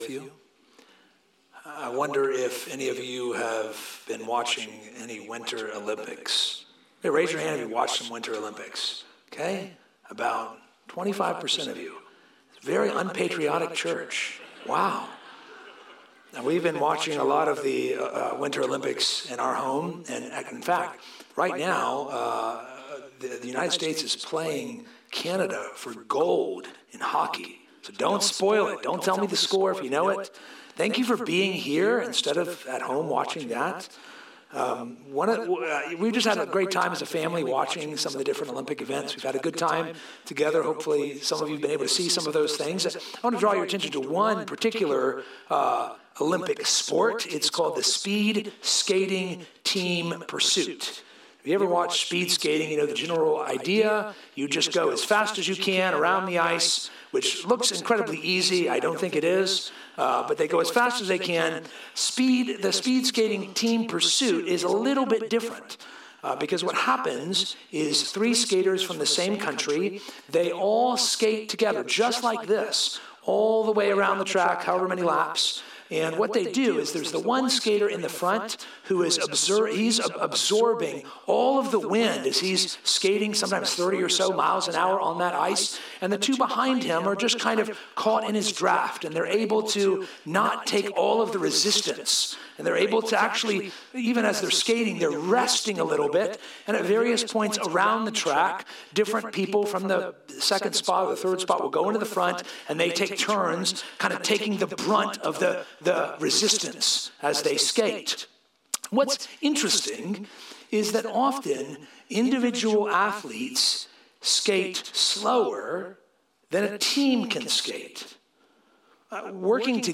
Sermons | Poquoson Baptist Church